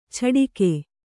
♪ chaḍike